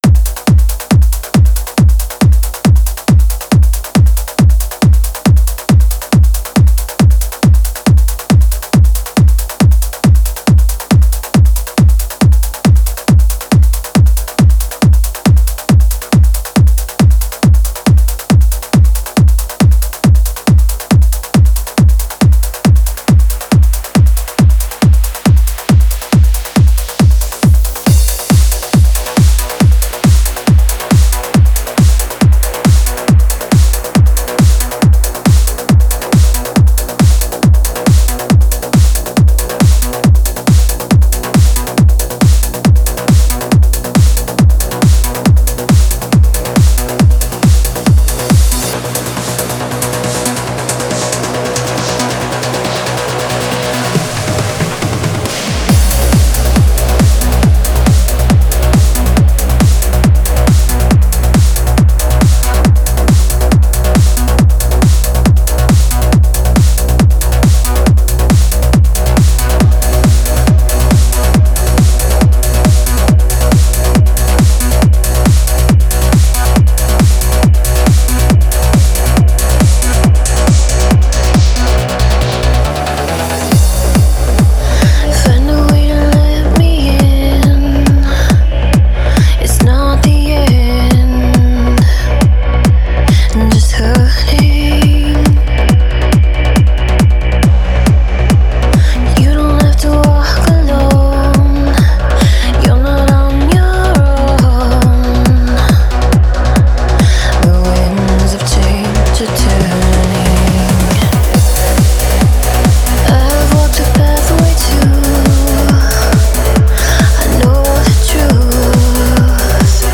Стиль: Vocal Trance